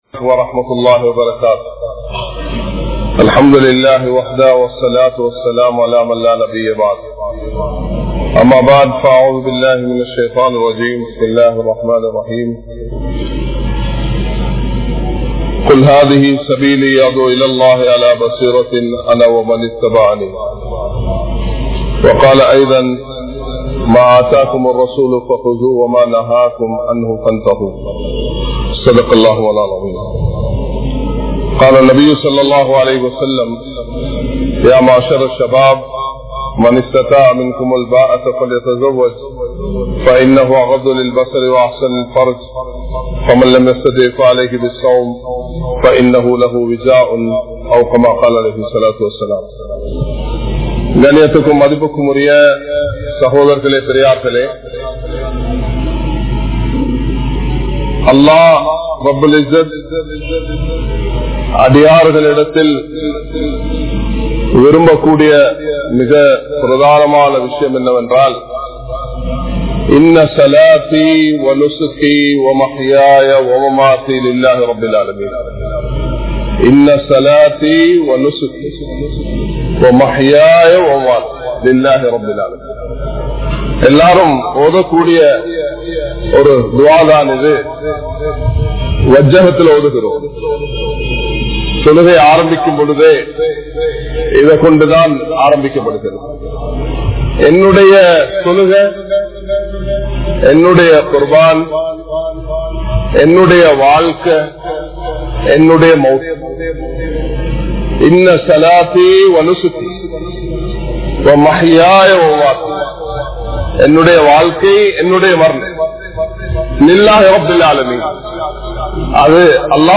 Thirumana Vaalkai | Audio Bayans | All Ceylon Muslim Youth Community | Addalaichenai